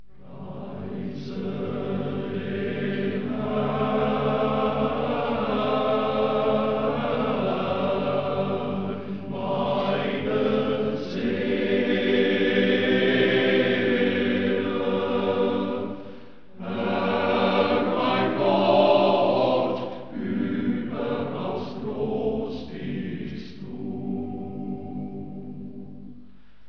Männerchor
Zur Zeit besteht er aus ca. 38 Sängern.
so klingt der Männerchor